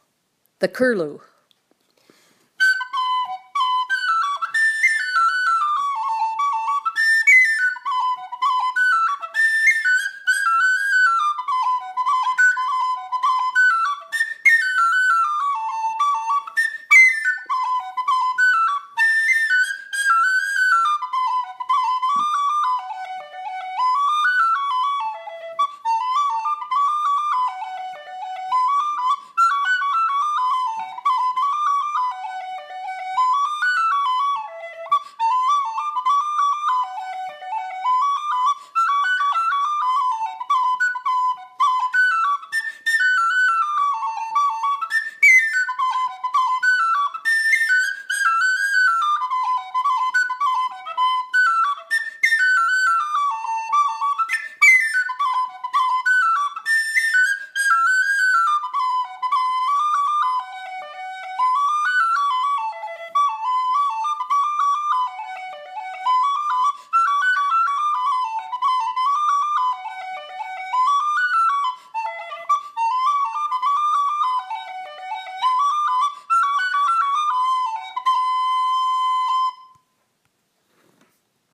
2016 Reels  Recordings  Sheet Music